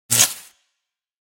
Electric Arc Sound Effect
Short and powerful electric spark with a sharp, high-voltage crackling sound. High-voltage zap noise. Short electric bursts.
Electric-arc-sound-effect.mp3